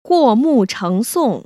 [guò mù chéng sòng] 꾸오무청쏭  ▶